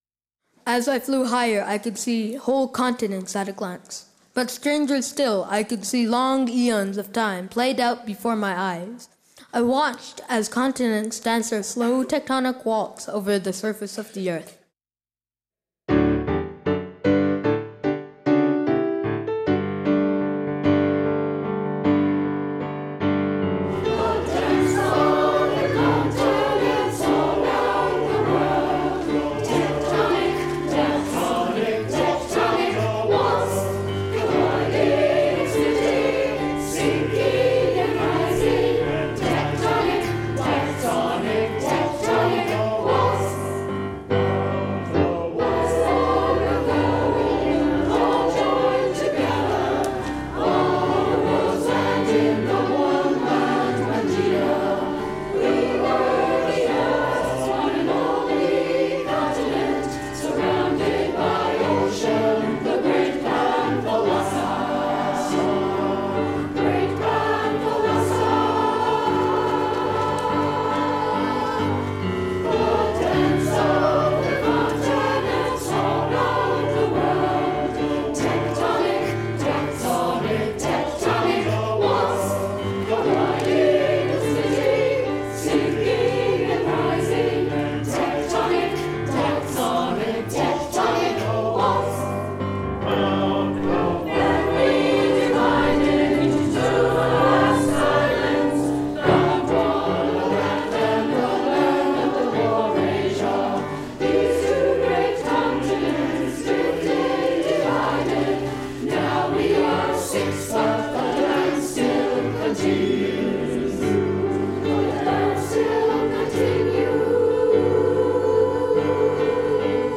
piano
Below are summaries and recordings of the songs of Powers of Ten as performed by the 2014 NCFO Festival Chorus.
• Tectonic Waltz – This lilting melody recounts the slow choreography of the continents over Earth’s lifetime.